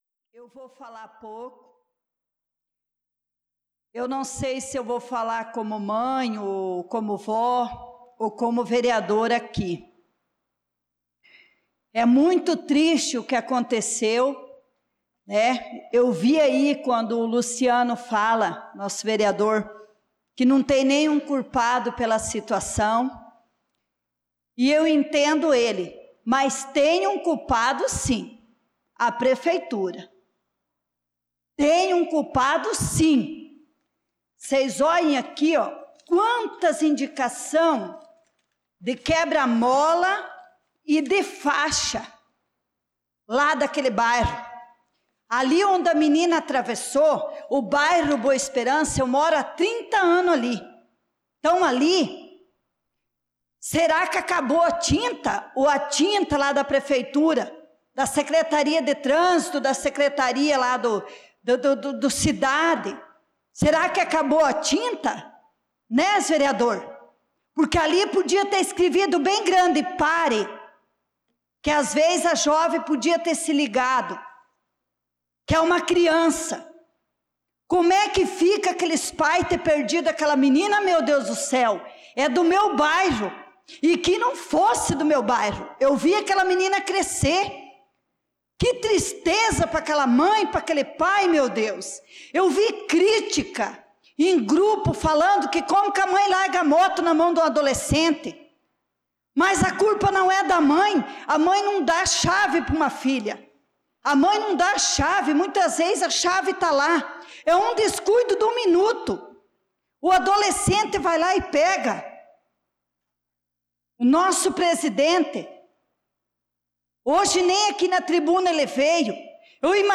Pronunciamento da vereadora Leonice Klaus na Sessão Ordinária do dia 23/06/2025.